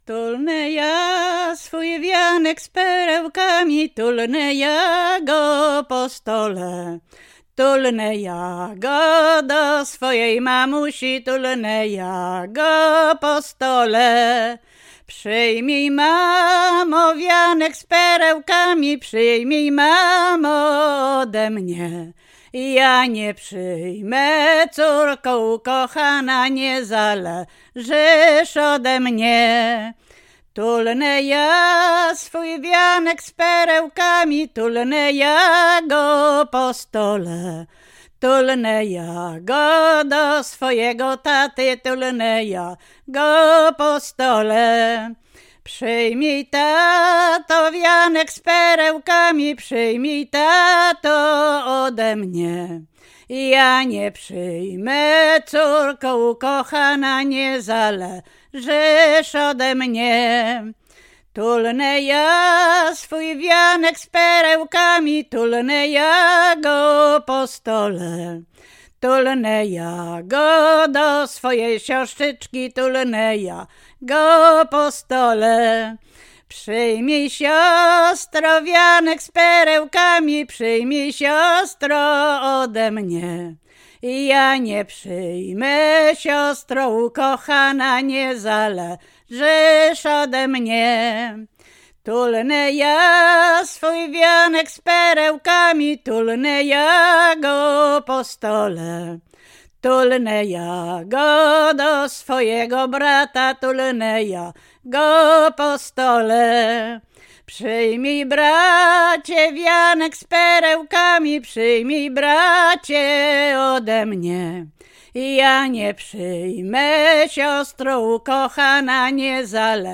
Łęczyckie
Weselna
wesele weselne oczepinowe